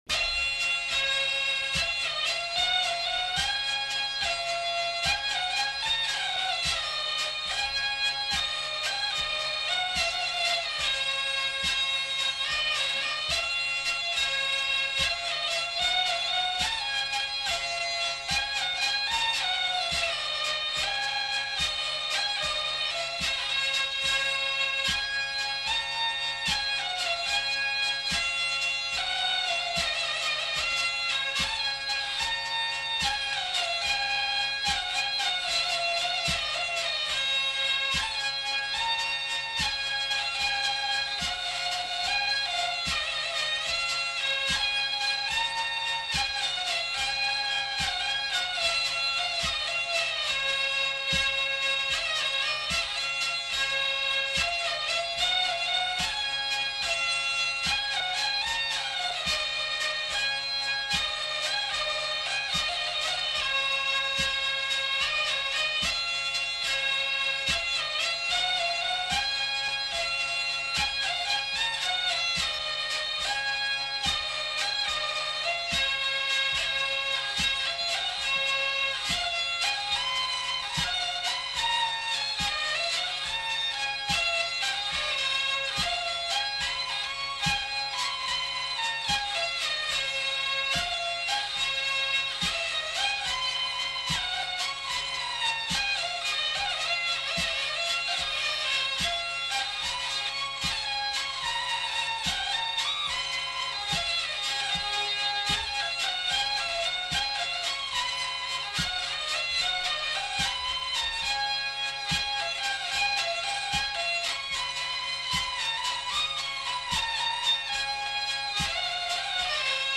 Valses
Genre : morceau instrumental
Instrument de musique : vielle à roue
Danse : valse
Notes consultables : Enchaînement de plusieurs thèmes ; le premier est Valse brune.